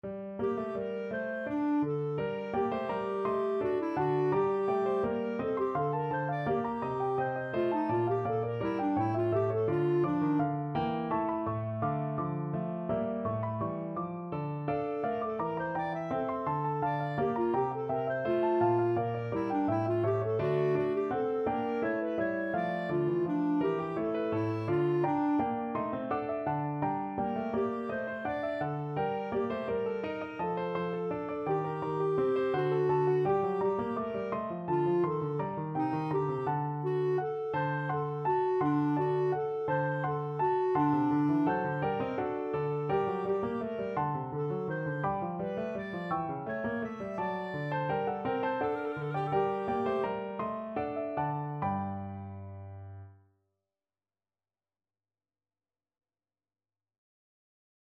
3/8 (View more 3/8 Music)
Classical (View more Classical Clarinet Music)